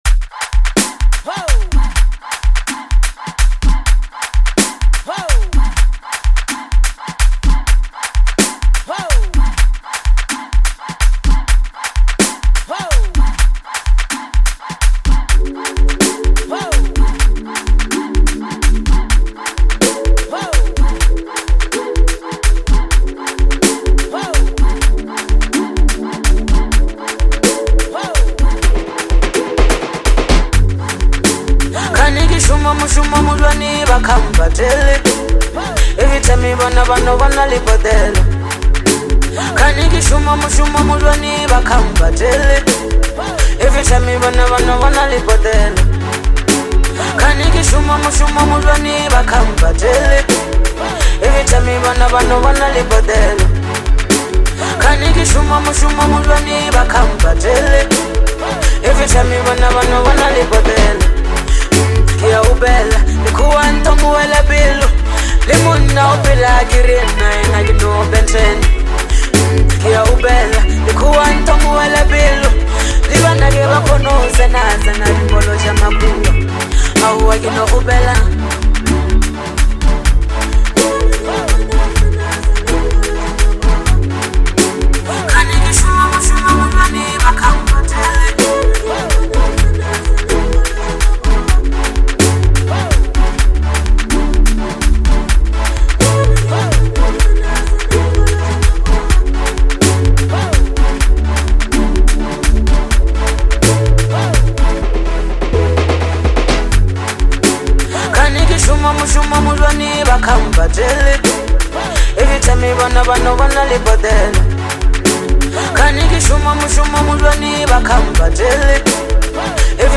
lekompo track